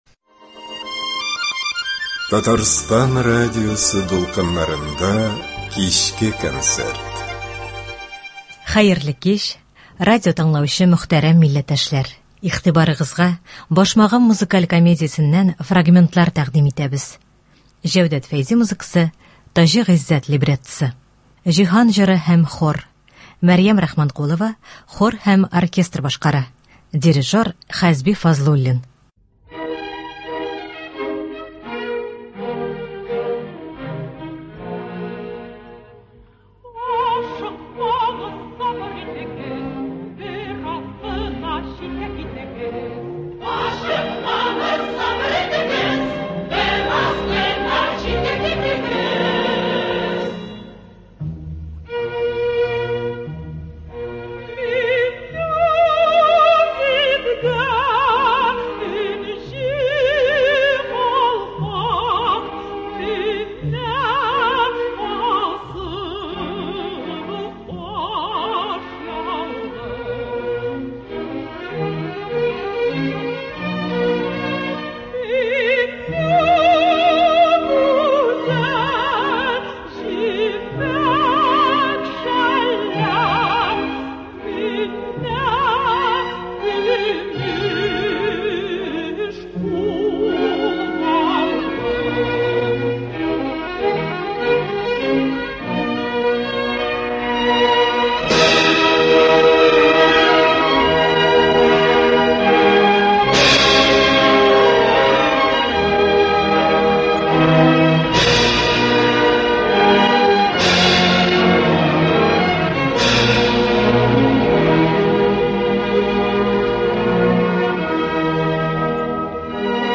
музыкаль комедиясеннән фрагментлар.